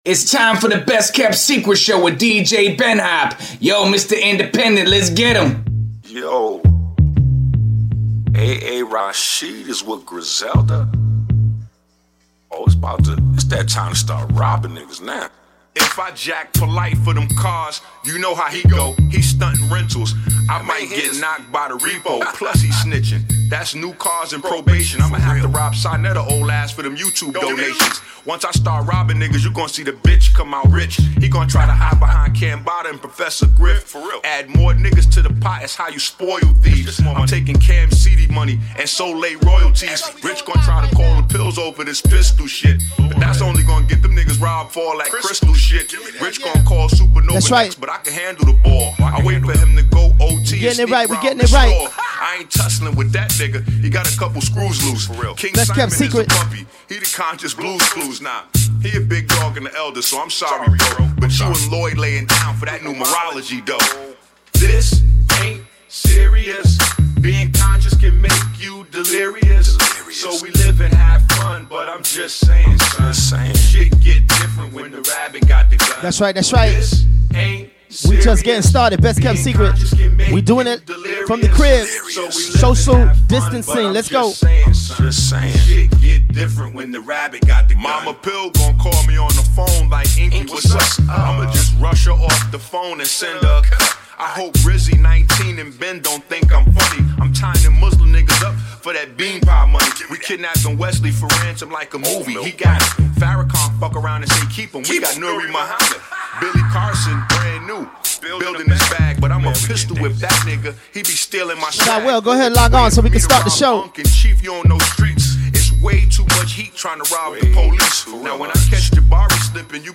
Showcasing Independent Artist from all over the world.